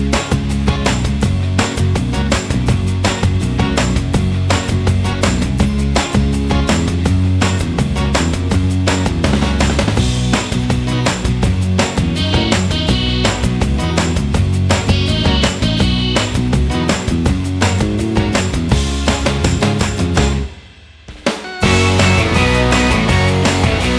Minus Main Guitar Rock 3:17 Buy £1.50